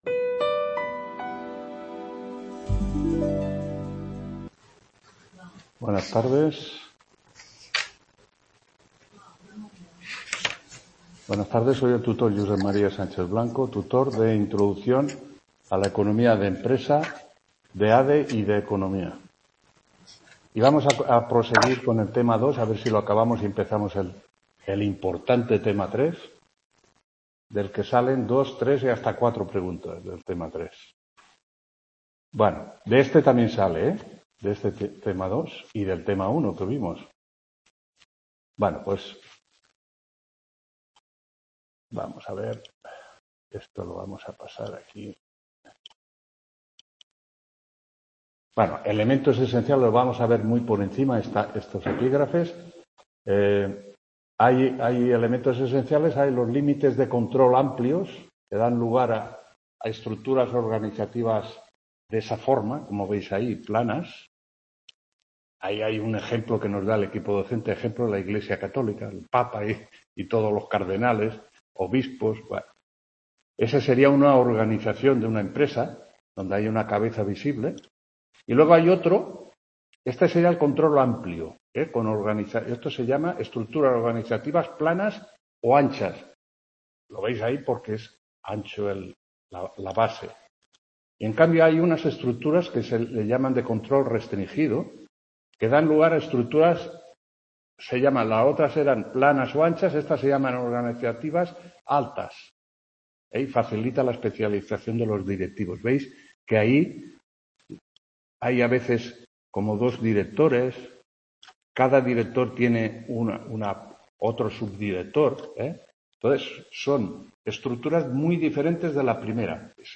3ª TUTORÍA INTRODUCCIÓN A LA ECONOMÍA DE LA EMPRESA 31…